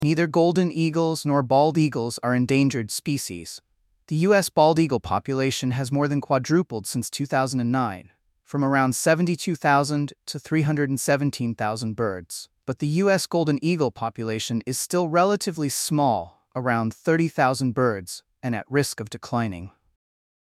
Sample answer: